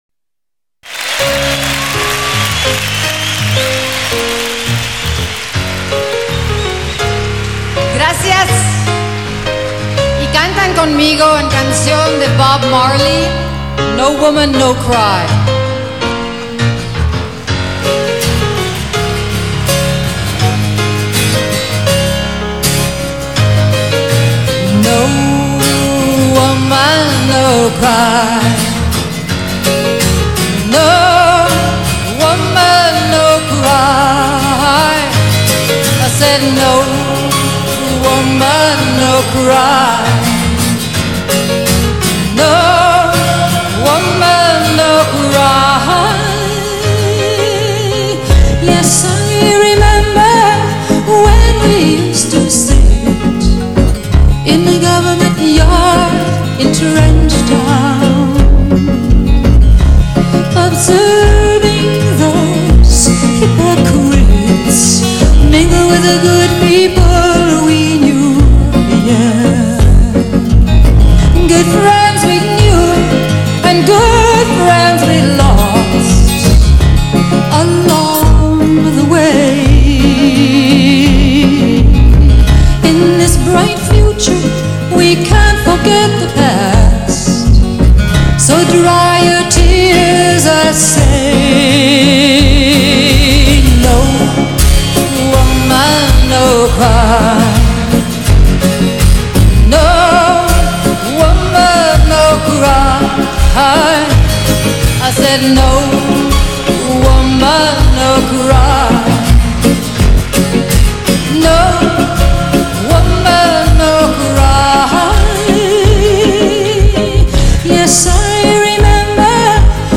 此ＣＤ也属天碟级录音．她通常用吉他自弹自唱，有时也配以乐队伴奏，舞台表演风格朴实无华。
虽是现场录音，但录音水准却备受赞赏。